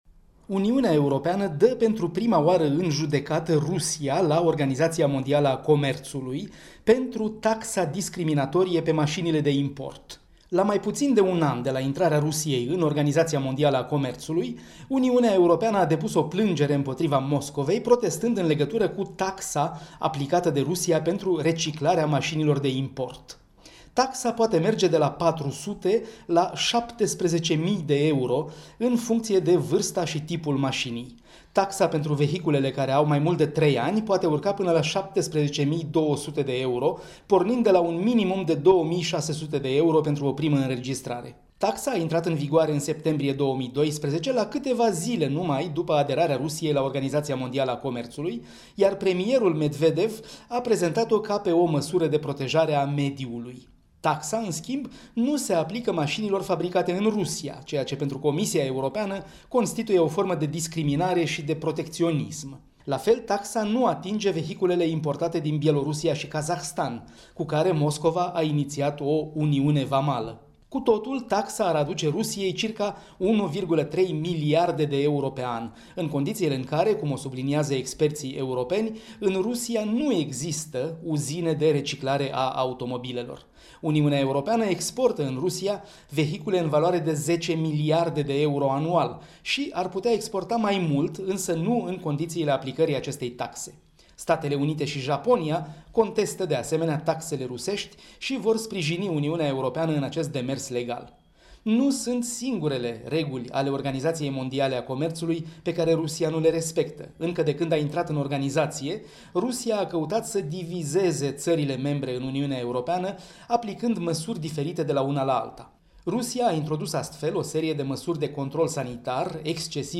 Corespondenţa zilei de la Bruxelles